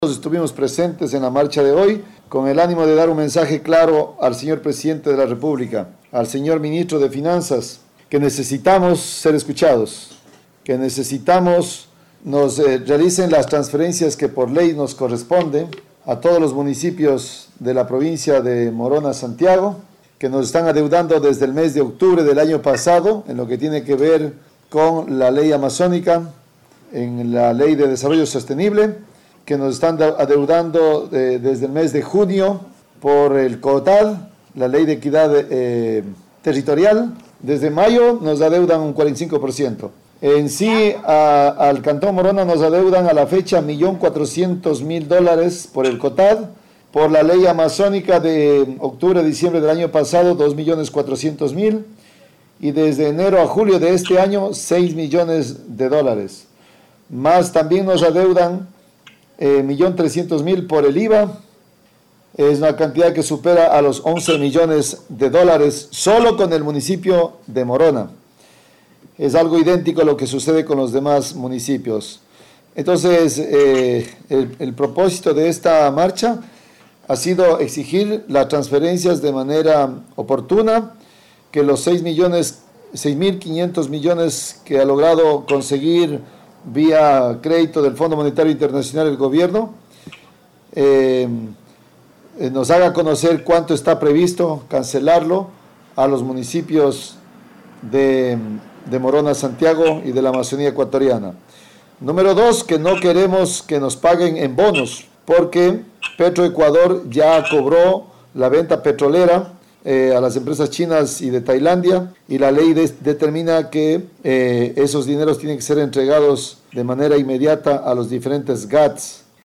Franklin Galarza, alcalde de Morona Santiago